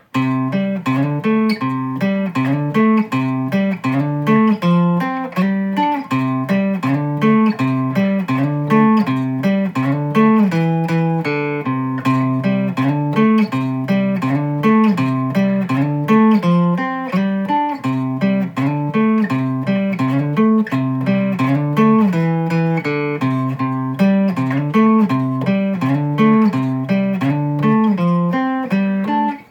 bludicka_basa_sloka.mp3